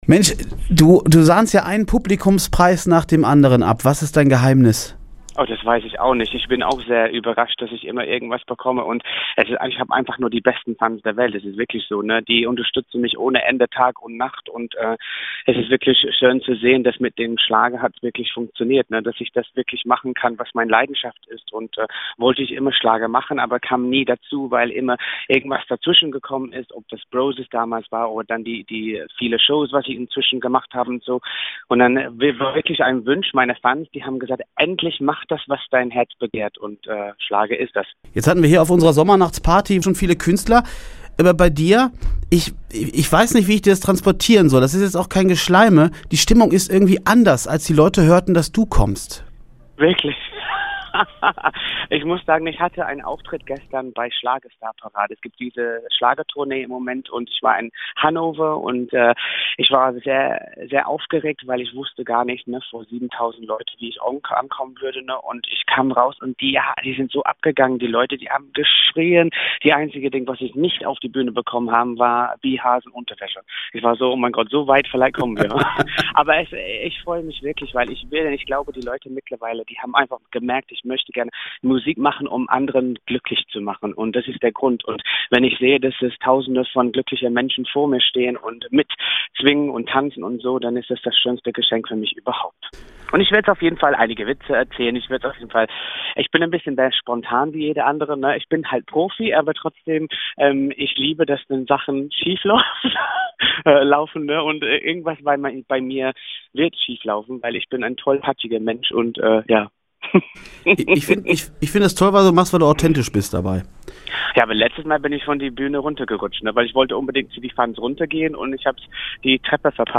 Ross Antony im Radio K.W. Interview
Mit freundlicher Genehmigung von Radio K.W. können Sie einen Mitschnitt des Interviews hier als mp3 herunterladen.